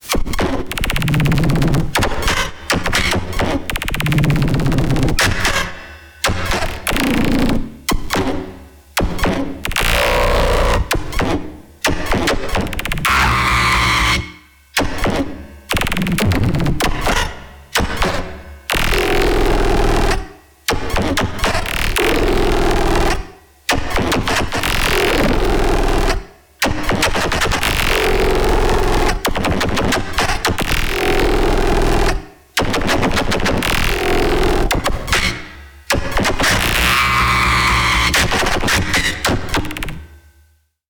Synthesis only, with a little bit of post processing in Bitwig.